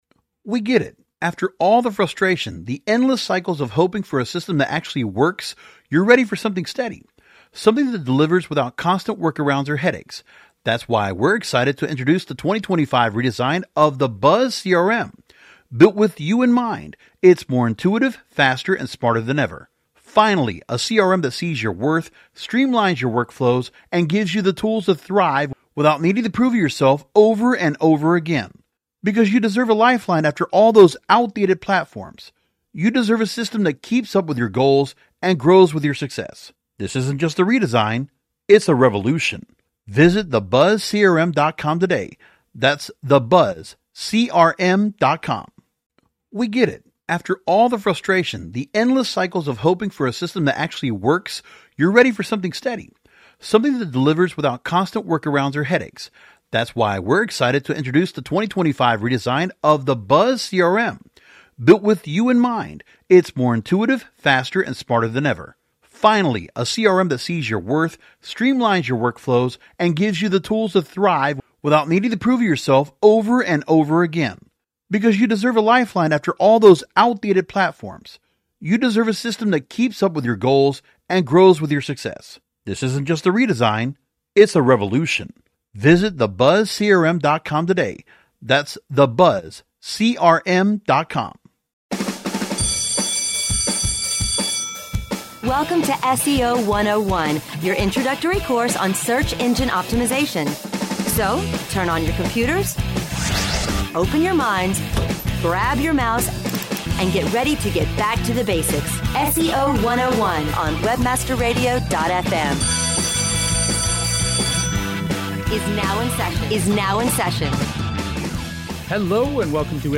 The hosts discuss Stephan Spencer’s points in his Ultimate Guide to Bot Herding and Spider Wrangling, updates to Google My Business reporting and Google Search Console, further evidence that Google Duplex is as good as it appears to be, and more.